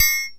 percussion 35.wav